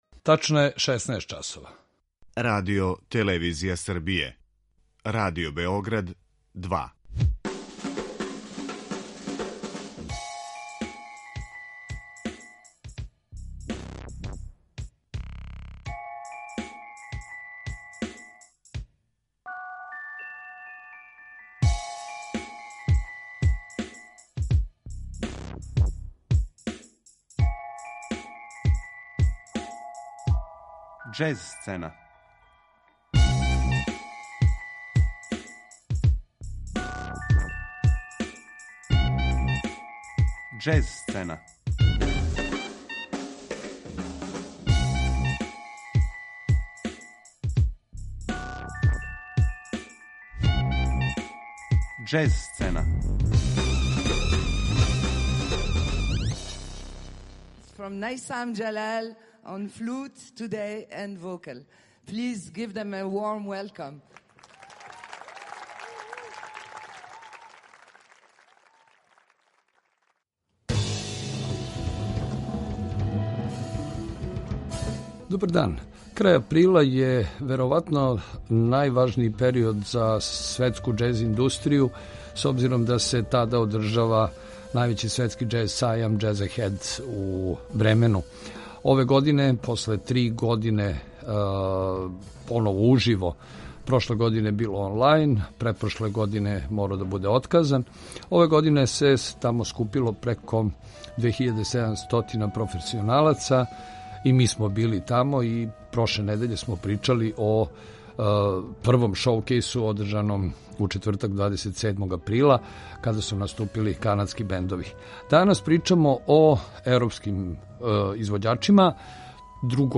Причу илуструјемо снимцима извођача са те манифестације.